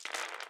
弹珠游戏音效
drop.ogg